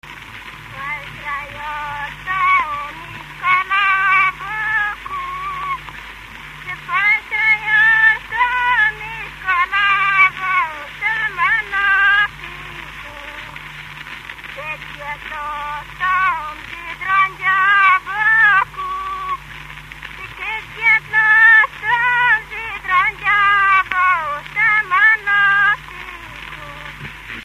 Moldva és Bukovina - Moldva - Bogdánfalva
Stílus: 8. Újszerű kisambitusú dallamok